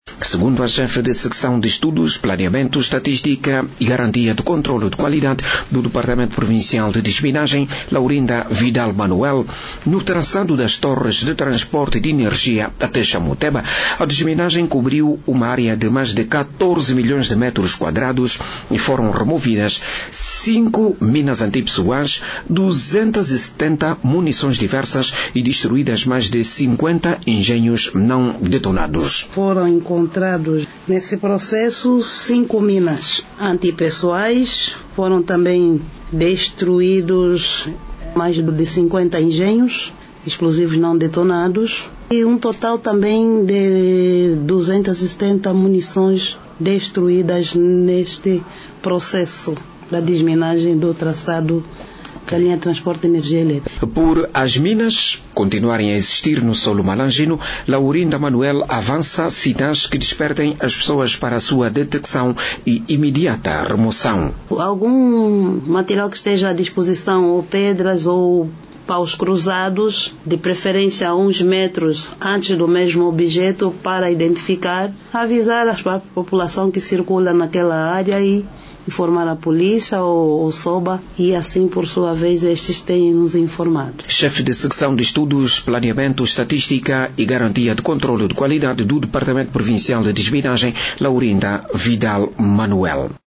MALANJE-DESMINAGEM-7HRS.mp3